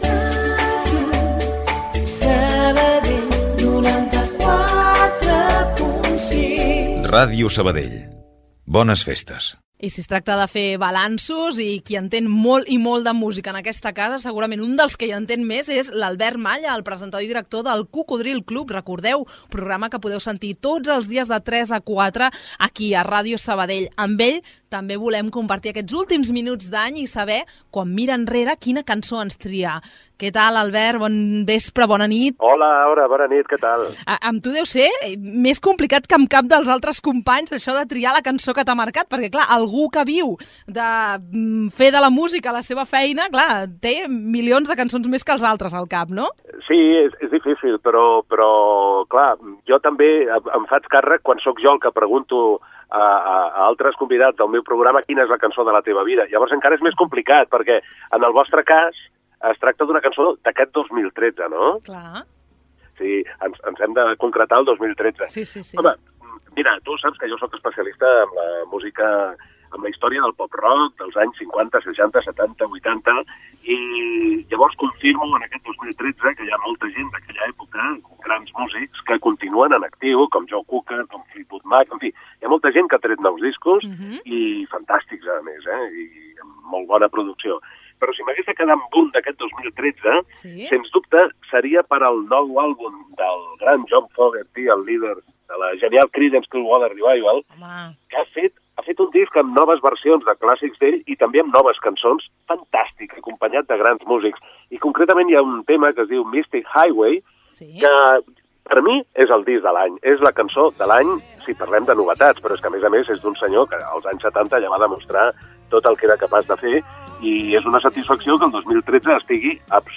Especial cap d'any. Indicatiu de l'emissora
Entreteniment